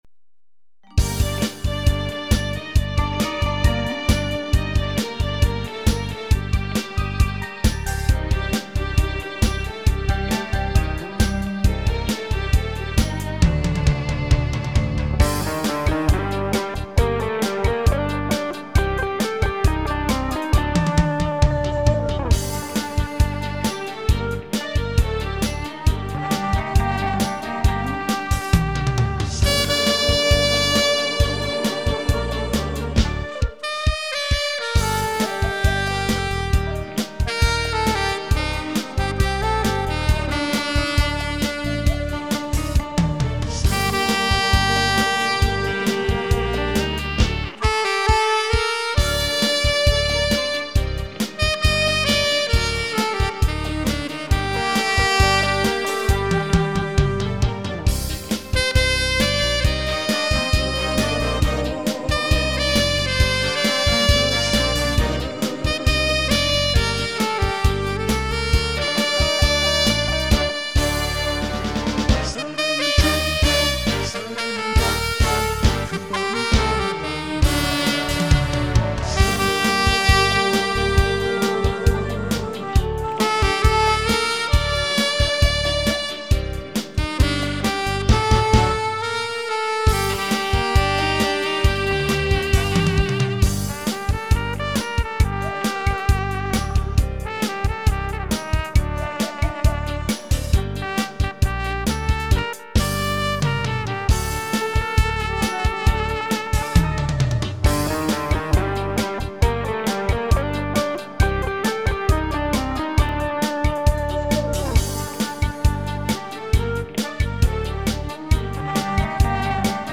색소폰을 배우고 있는 친구(6개월 차)가 아침에 그동안 연습한 연주 녹음파일을 보내줘서 투척합니다.